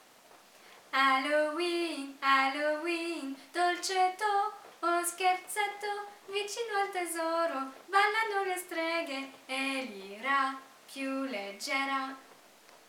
♫ (Melodia di Fra’ Martino)